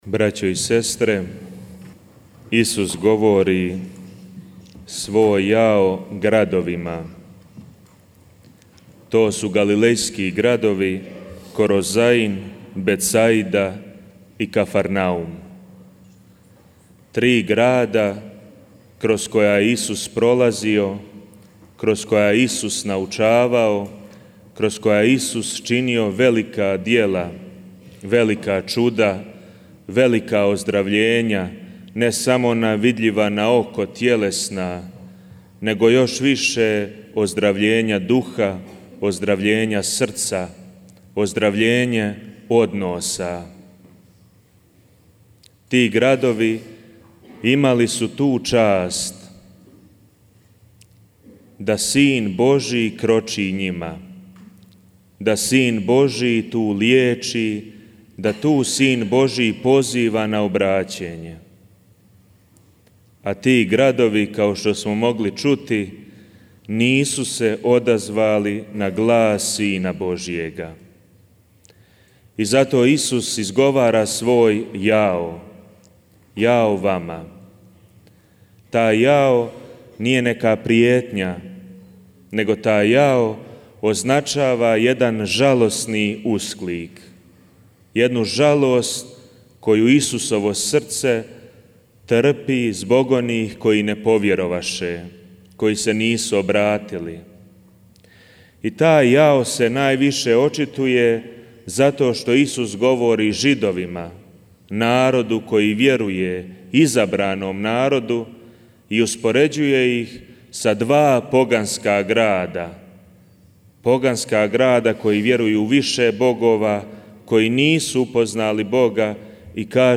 Obred preminuća svetog Franje i misa u Međugorju - Radio Mir
Večeras, na uočnicu svetkovine sv. Franje franjevačkim crkvama nakon večernje svete mise bio je Obred preminuća sv. Franje pa tako i u Međugorju.